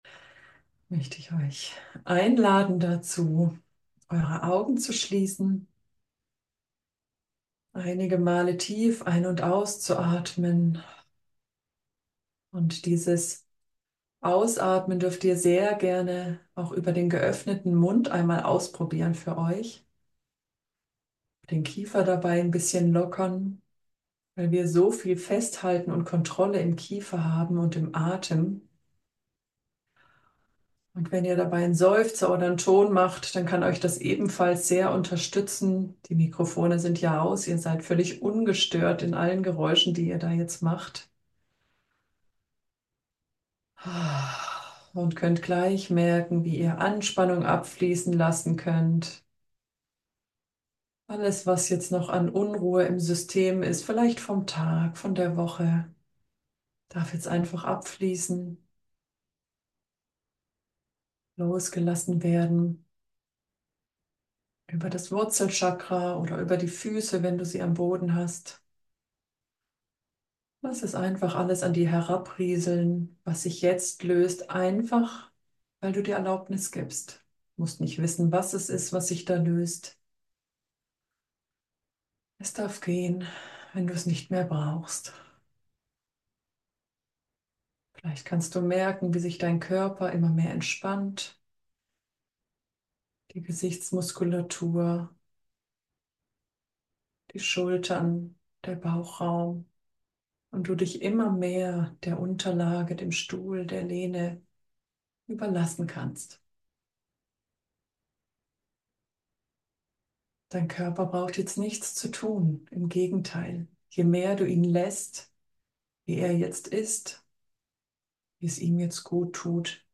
Aufzeichnung Workshop – Feld der Liebe
- Aufzeichnung LIVE Workshop -